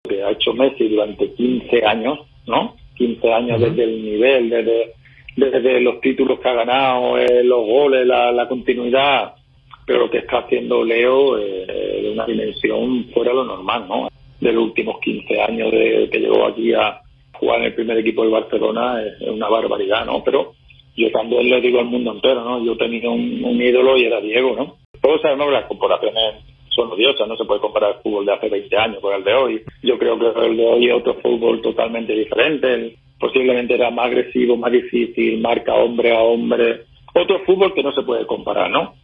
(Fernando Hierro en Radio Continental)
“Lo que ha hecho Messi durante 15 años de gran nivel y los títulos que ha ganado, los goles y su continuidad son de una dimensión fuera de lo normal. Desde que llegó al primer equipo del Barcelona es una barbaridad; pero yo también le digo al mundo entero, yo tenía un ídolo y era Diego”, sostuvo Hierro en una extensa nota con Radio Continental de Buenos Aires.